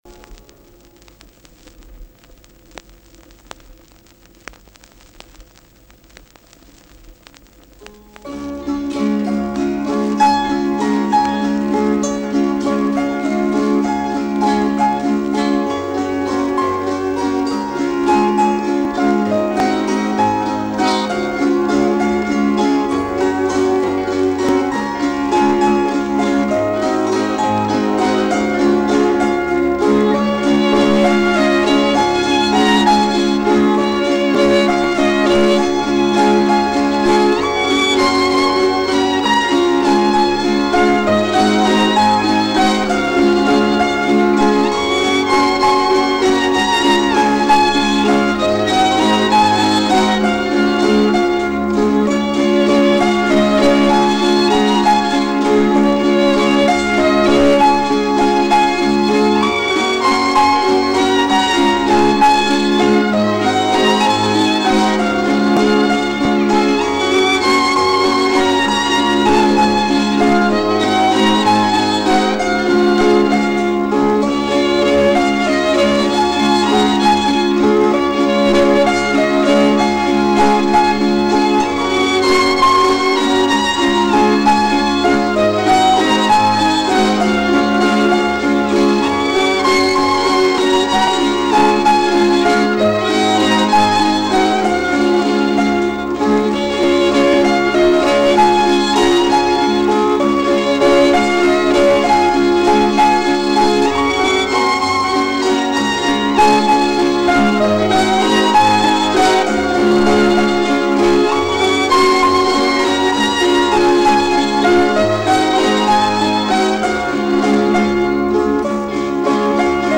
valss_orn_kevade_orig.mp3